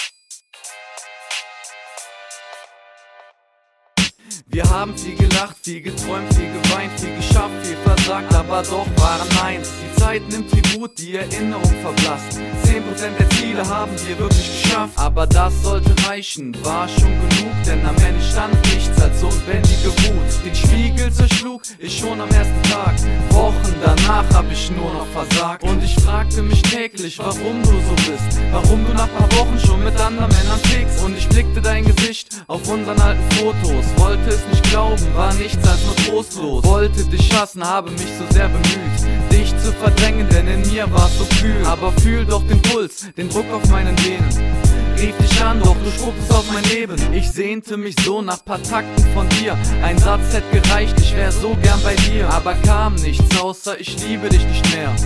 HipHop Vocals klingen immer sehr dünn
Ich nutze: TBone - SC450 Audiointerface FocusRite Scarlett 2i2 + einen Micscreen von Tbone zum Recorden nutze ich Cubase.